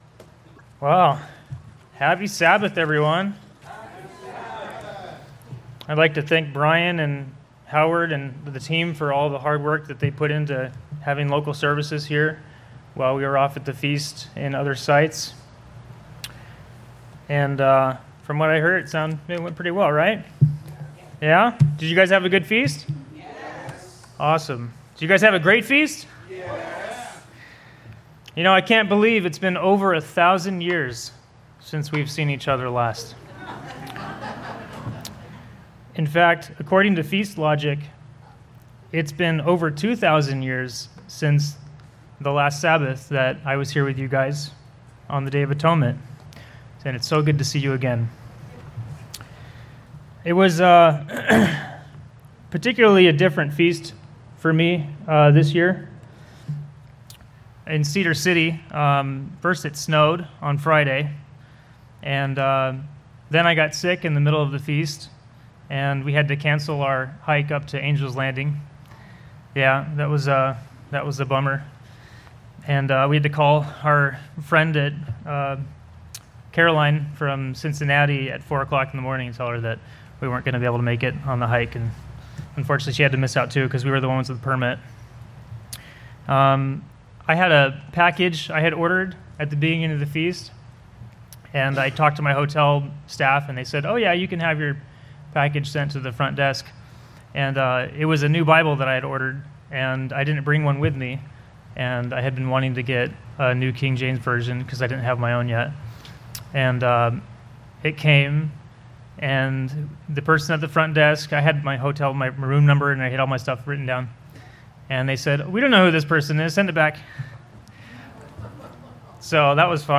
Sermons
Given in Orange County, CA